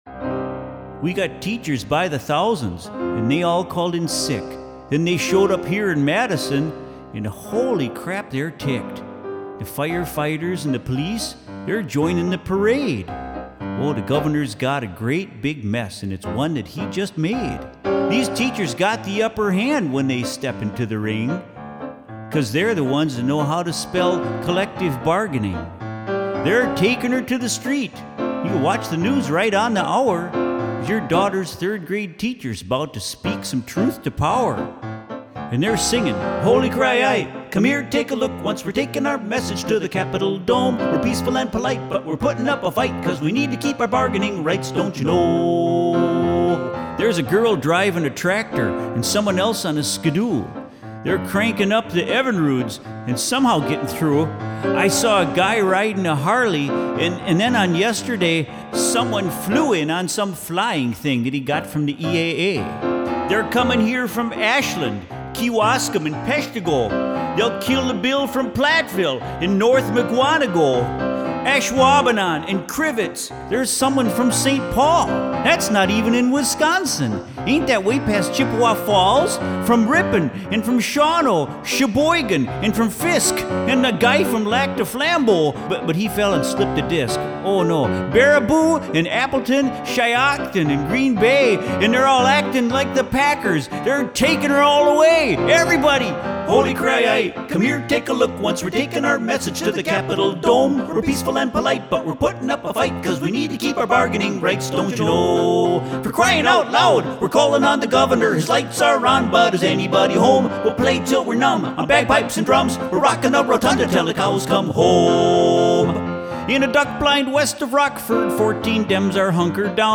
pro-union marching song
The result is a timely and humorous protest song that you can hear by clicking the link below.